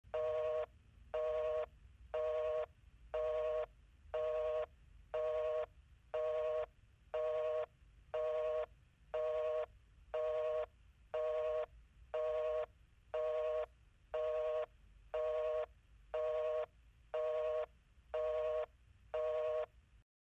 PIANETA GRATIS - Audio Suonerie - Telefoni e Fax - Pagina 07
phone-busy-1 (2).mp3